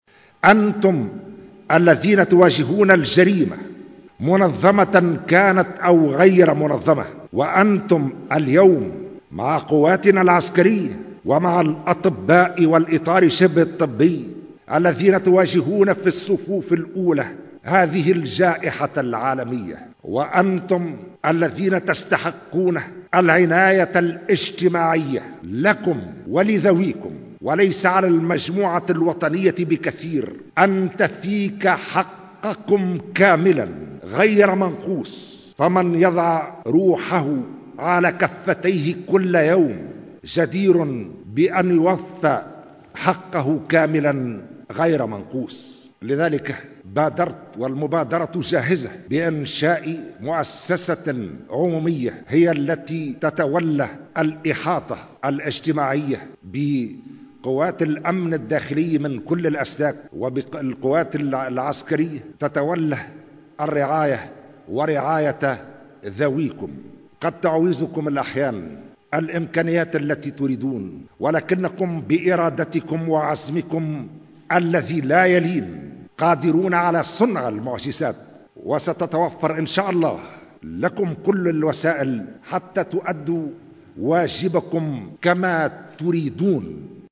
أعلن رئيس الجمهورية قيس سعيّد اليوم السبت 18 أفريل 2020، بقصر قرطاج، خلال إشرافه على موكب الاحتفال بالذكرى 64 لعيد قوات الأمن الداخلي، عن مبادرة رئاسية، بإنشاء مؤسسة عمومية تتولى الإحاطة الاجتماعية بقوات الأمن الداخلي من كل الأسلاك والقوات العسكرية، و تتولى رعاية الأمنيين و ذويهم، حسب تعبيره.